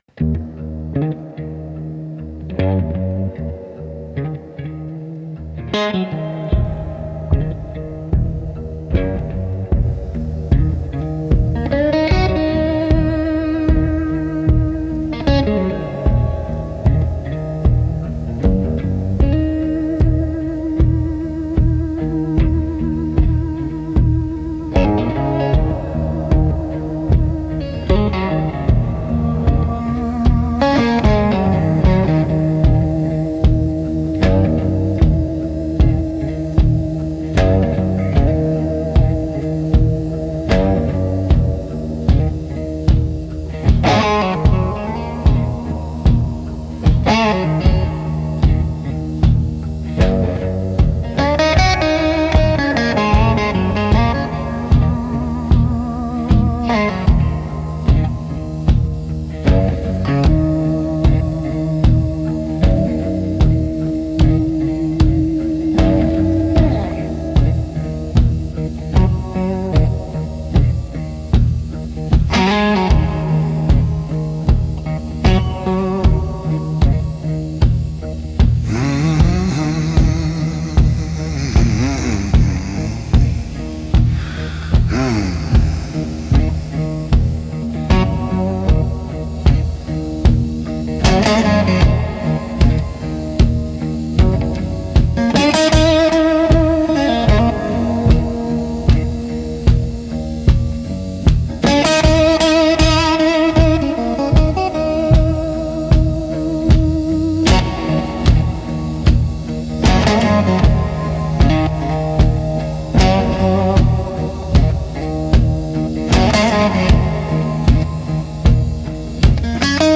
Instrumental - Sermon in Open G